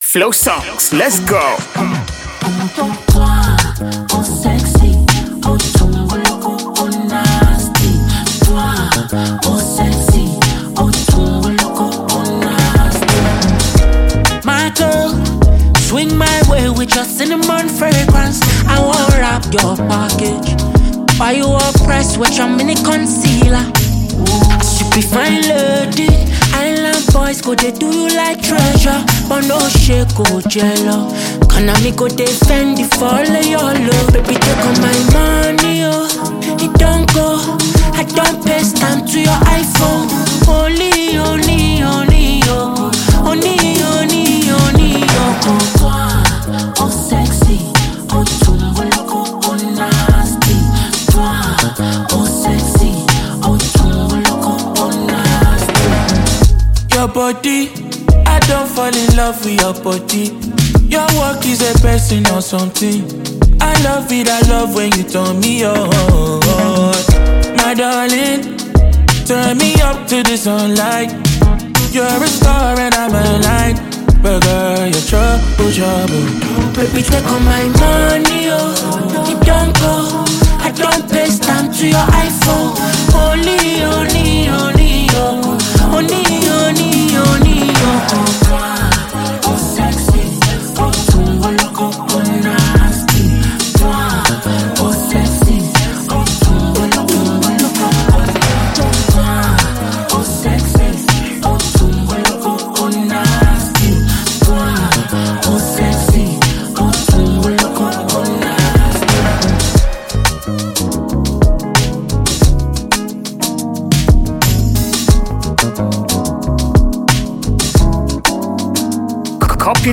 It is a catchy and lively melody.
Afrobeat